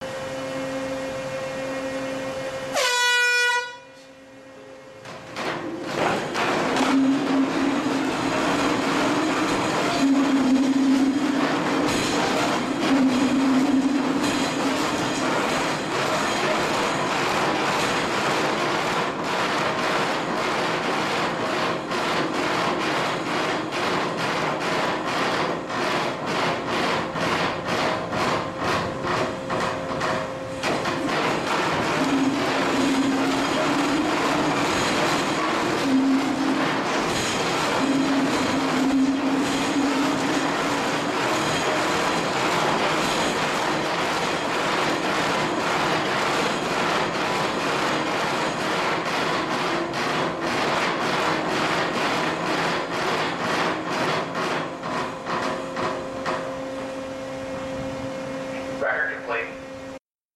描述：在圣地亚哥大学的巨型摇桌上进行地震测试
Tag: 场记录 地震 摇表